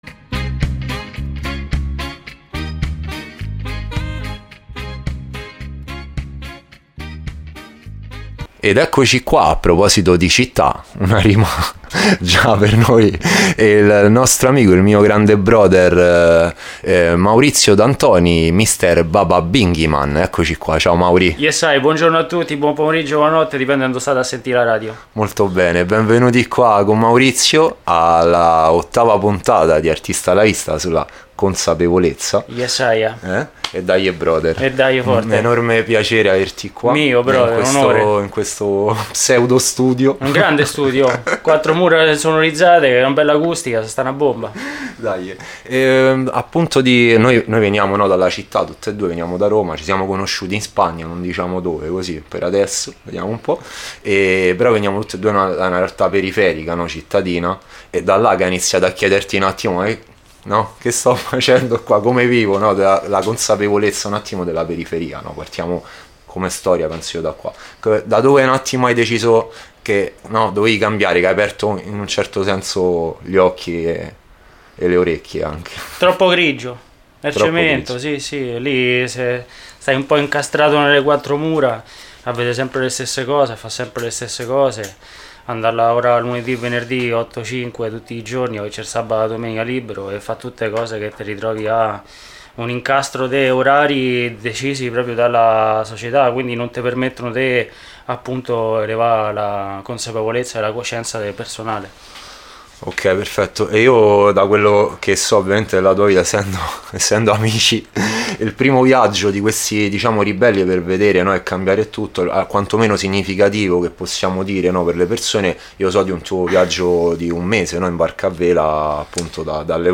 INTERVISTA | ARTISTA A LA VISTA
Nel corso dell’intervista ci ha deliziato con due canzoni piene di messaggi e positività. Chiacchierata intensa e divertente che ci ha avvolto emotivamente e da cui abbiamo tratto molti spunti interessanti.